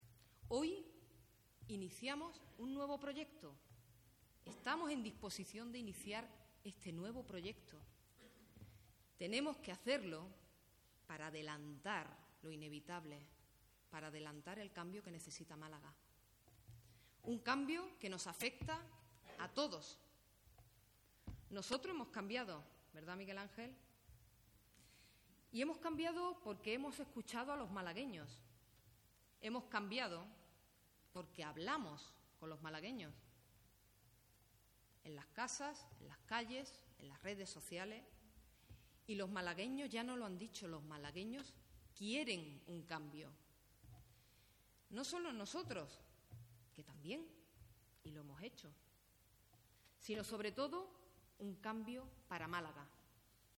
La candidata socialista en Málaga capital, María Gámez, ha asegurado hoy en su acto de presentación en el que ha participado el secretario general de los socialistas andaluces y presidente del Gobierno andaluz, José Antonio Giiñán, que "hoy iniciamos un nuevo proyecto para adelantar el cambio que Málaga necesita,un cambio que nos afecta a todos".